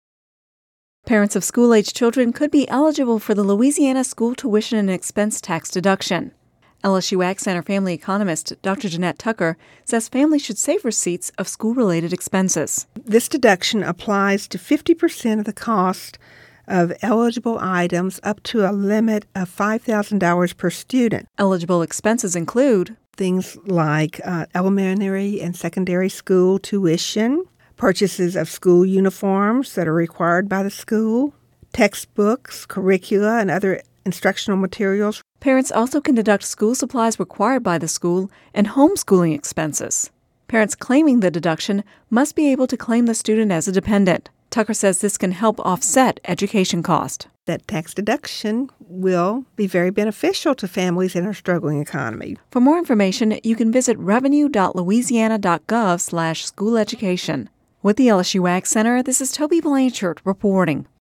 (Radio News 08/23/10) Parents of school-aged children could be eligible for the Louisiana School Tuition and Expense Tax Deduction.